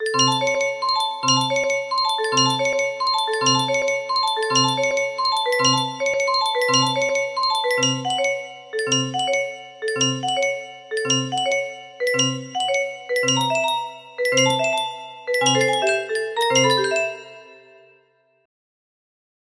Unknown Artist - macy music box melody